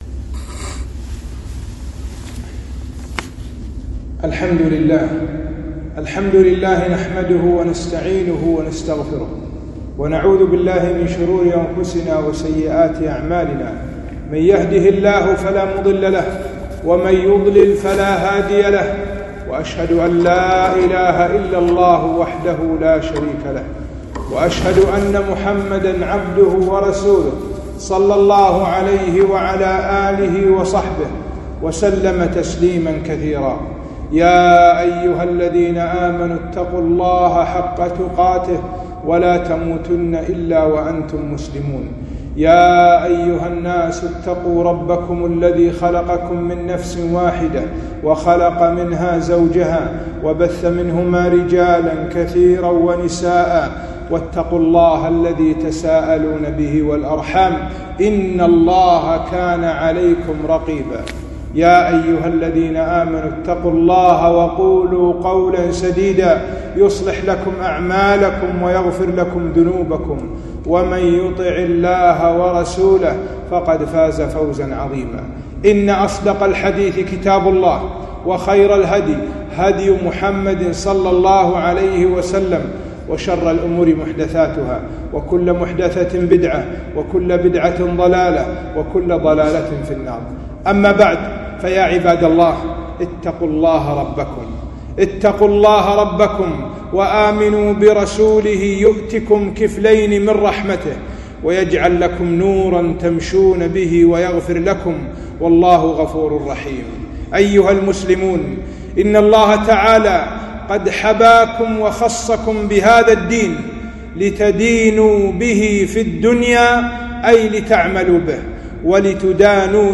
خطبة - وجوب الحجاب وخطر التبرج